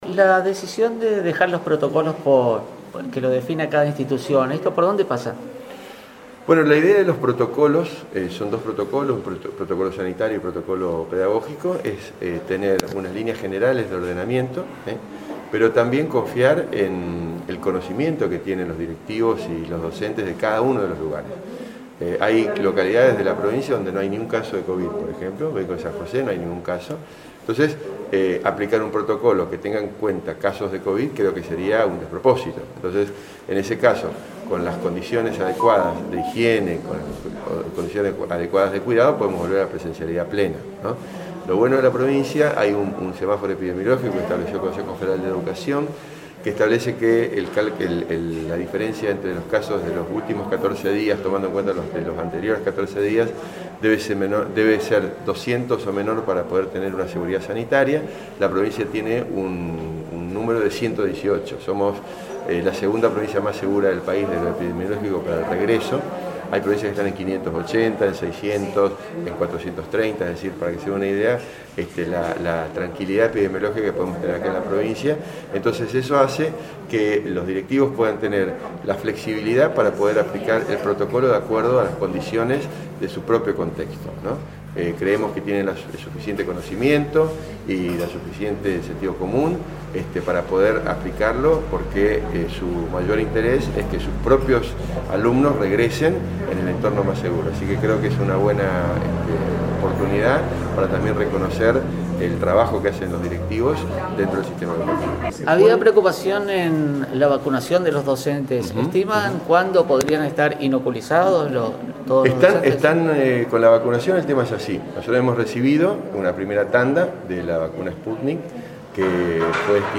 En su visita a la Capital Nacional de la Yerba Mate el ministro de Educación de la Provincia de Misiones, Miguel Sedoff, explicó el por qué de la decisión de dejar los protocolos en manos de los establecimientos educativos, el tema de la vacunación docente y brindó el dato de cantidad de escuelas visitadas donde la presencialidad sería del 100%.
En rueda de prensa con los medios